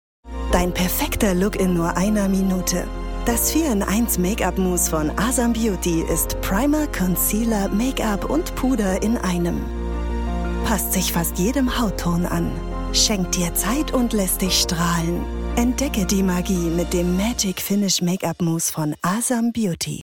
Sprecherin in Berlin, klare und warme Stimme, Mezzosopran, spricht Werbung, Dokumentation, Imagefilme, Audioguides, Hörbuch, Hörspiel, E-Learning, Games, Erklärfilm, Voice over, Telefonansage
Sprechprobe: Werbung (Muttersprache):